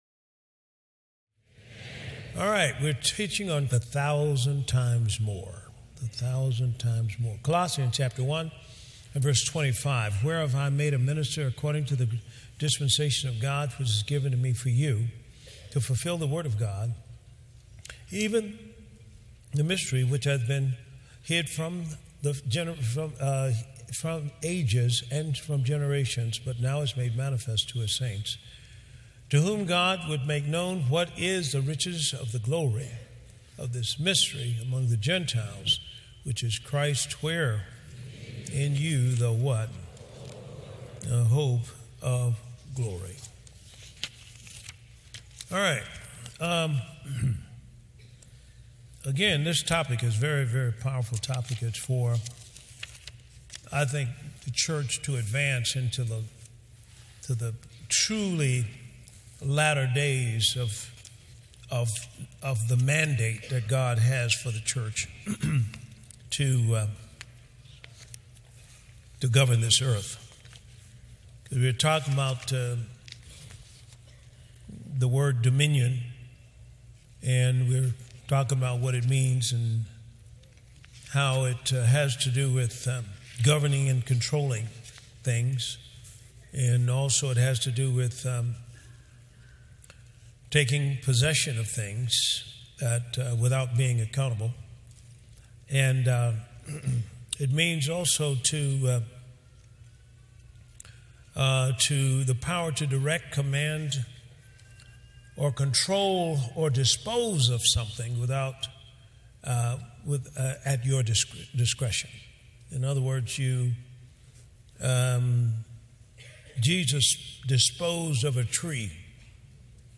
(Single Teaching) God plus you equals the majority and He will supernaturally empower you for your assignment with the potential to do a thousand times more far above the world's way of doing things.